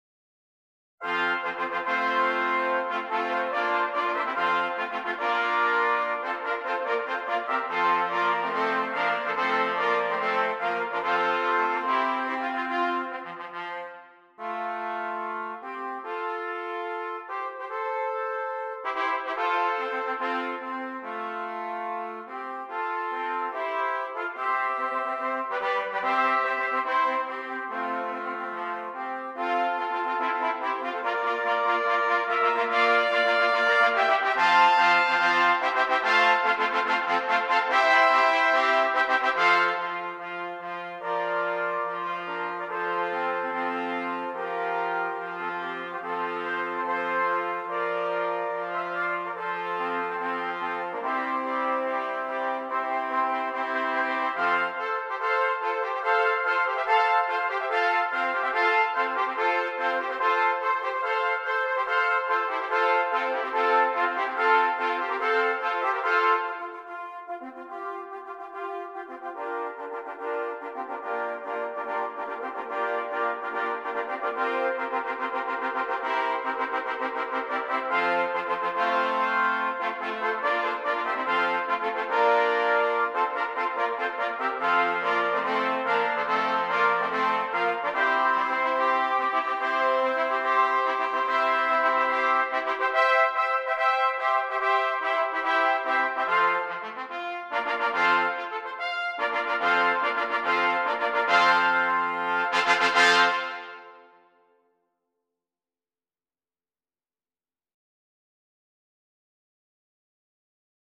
Brass
6 Trumpets